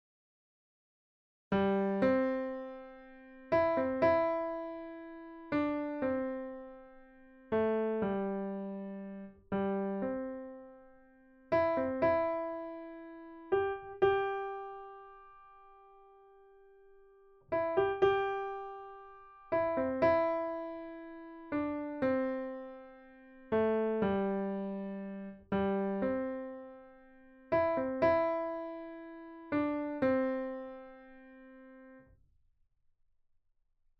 All the melodies have been transported to fit the Do Major Pentatonic scale in the key of C, which is the one we have studied so far.
Although the tempo indication is the same as before (120 BPM), the overall mood suggests more flexibility in following the beat, particularly at the end of each phrase.
This slow-paced, very expressive and popular song was conceived in the XVIII century, but you can hear it on every corner even today.